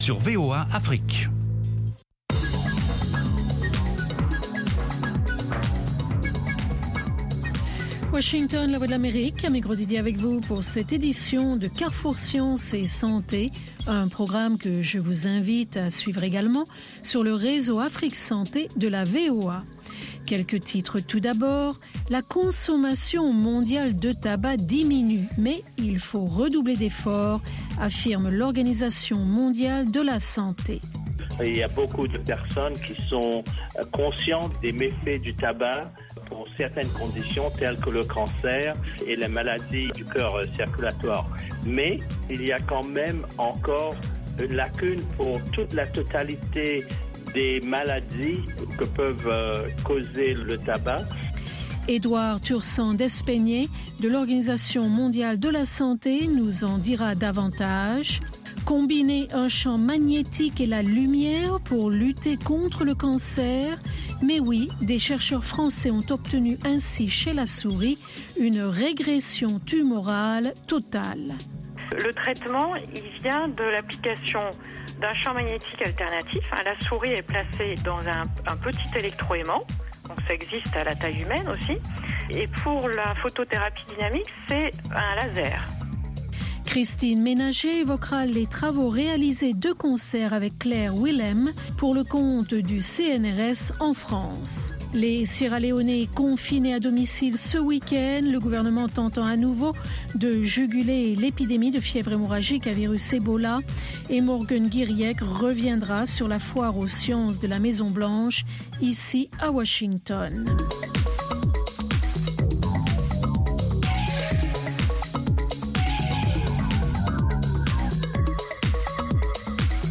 Carrefour Sciences et Santé vous offre sur la VOA les dernières découvertes en matière de technologie et de recherche médicale. Il vous propose aussi des reportages sur le terrain concernant les maladies endémiques du continent africain.